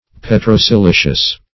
Search Result for " petrosilicious" : The Collaborative International Dictionary of English v.0.48: Petrosilicious \Pet`ro*si*li"cious\, a. Containing, or consisting of, petrosilex.